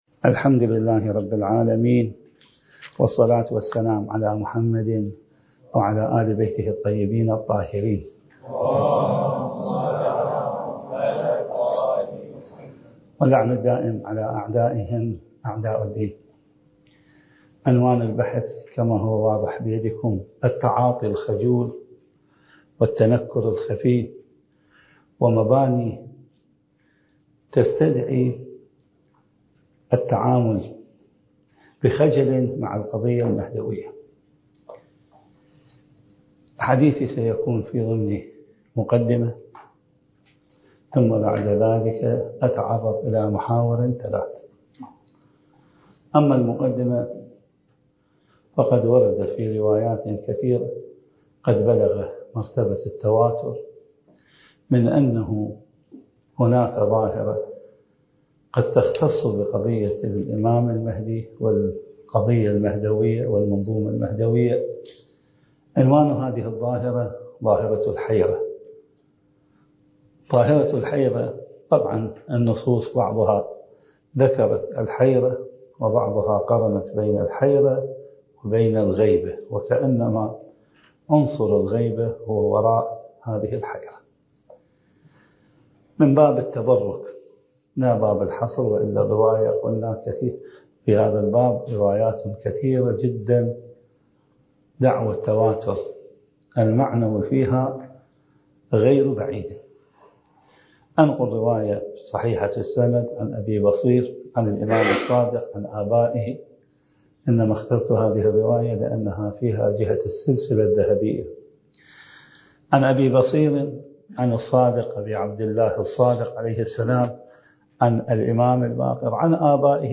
الدورة المهدوية الأولى المكثفة (المحاضرة السابعة)